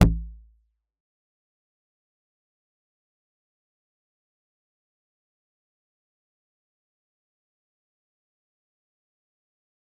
G_Kalimba-D1-f.wav